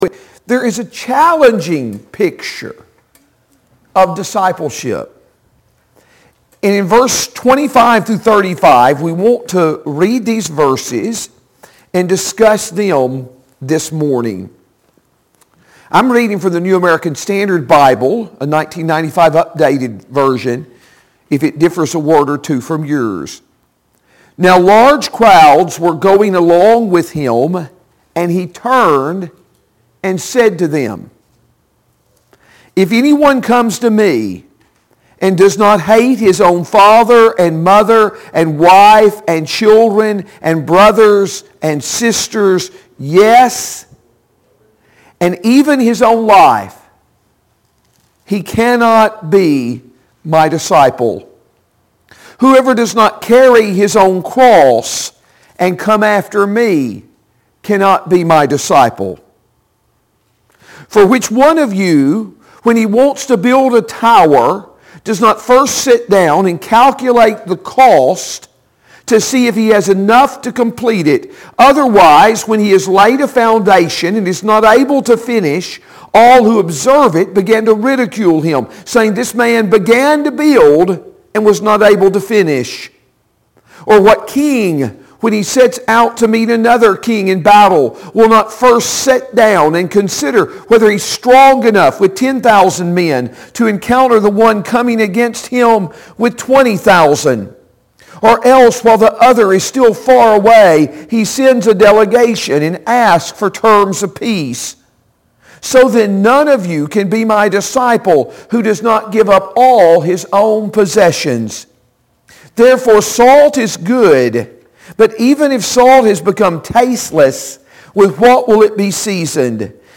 Sunday AM Bible Study Period